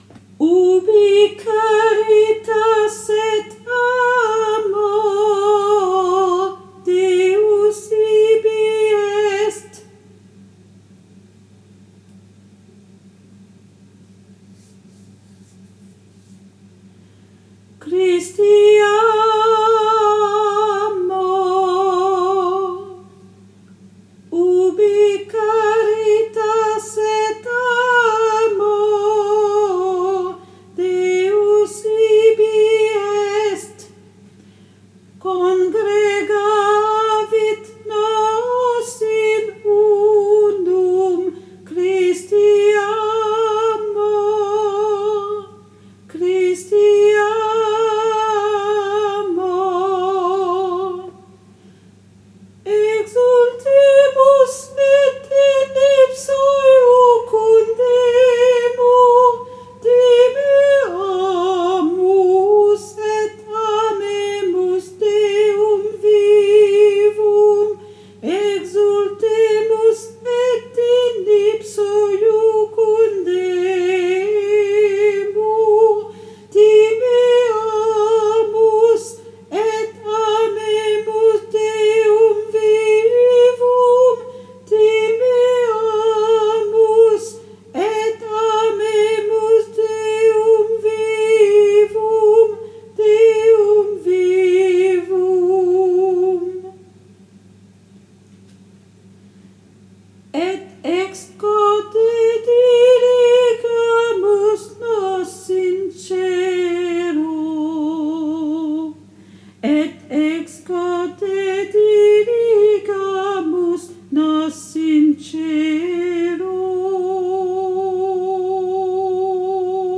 MP3 versions chantées
Mezzo-soprano